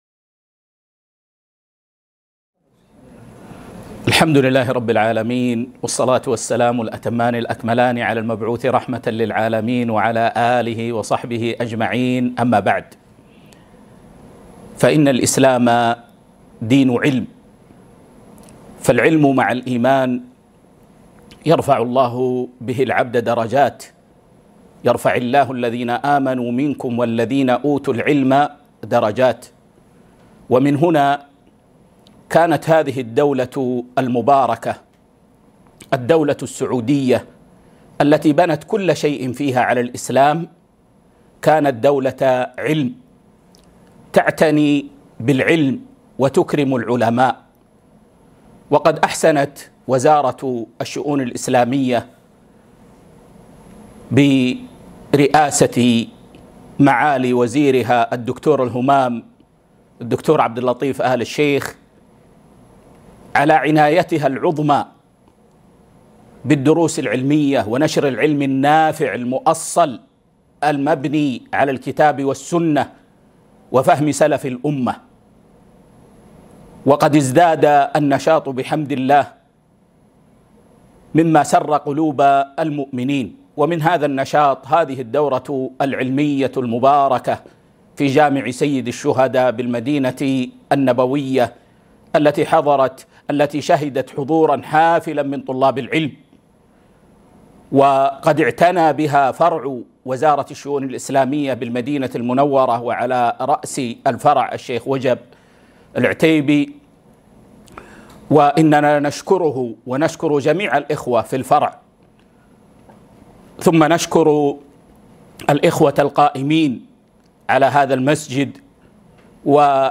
كلمة ختام الدورة العلمية الأولى بجامع سيد الشهداء